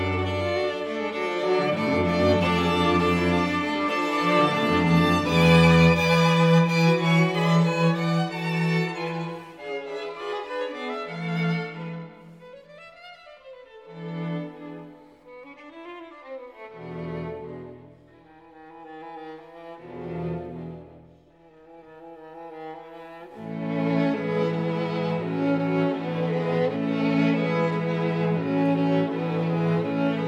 Streichquartett Nʻ1, op. 11, ré majeur / Piotr Ilyitch Tchaikovsky
0 => "Musique de chambre"